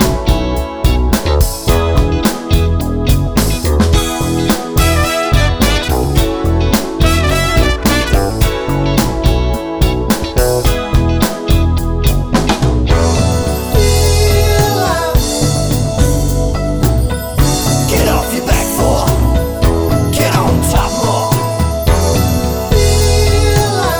Live Version Pop (1980s) 4:22 Buy £1.50